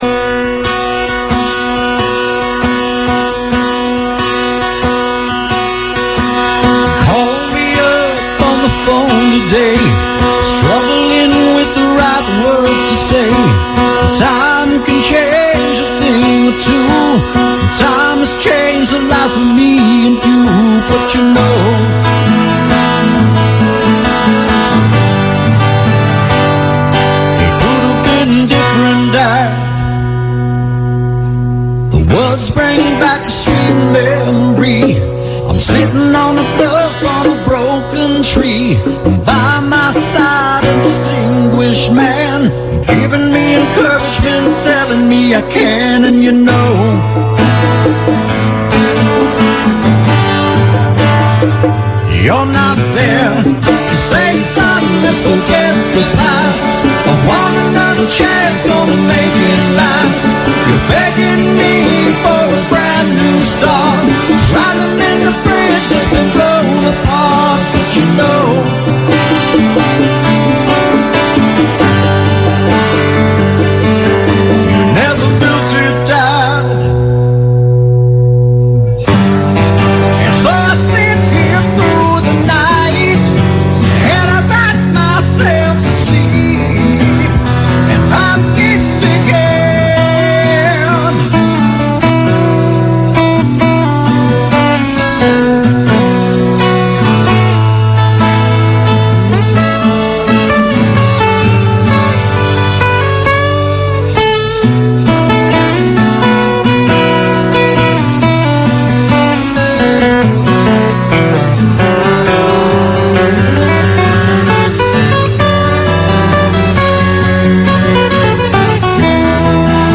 Akustikversion in einem Radiostudio in Mailand aufgenommen.